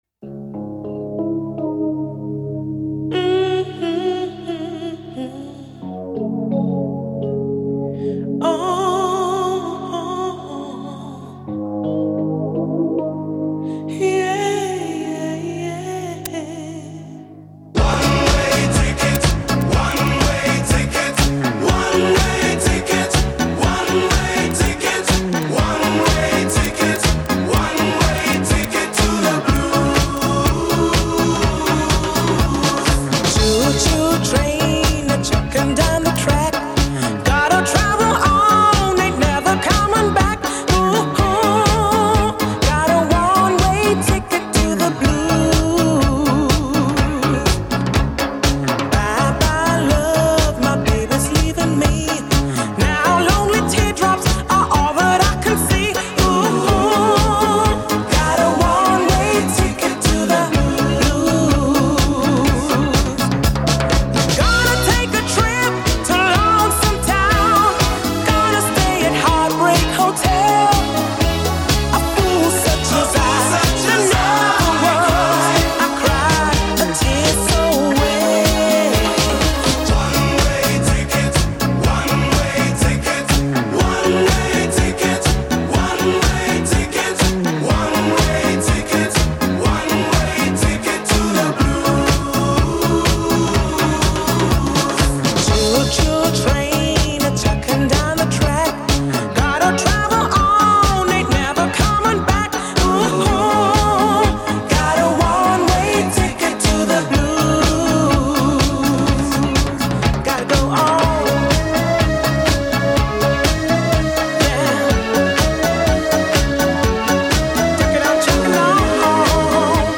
那时跳舞必放的一首舞曲。